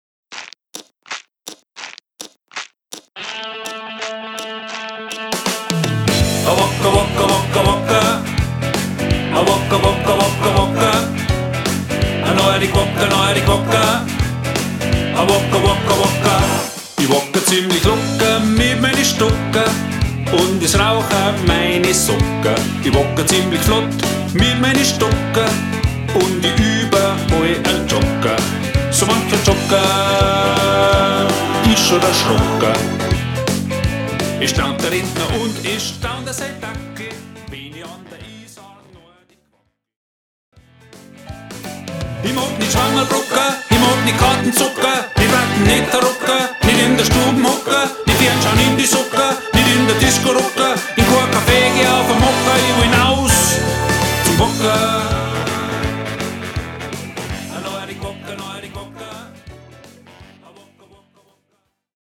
bavarian version
rockin� guitar version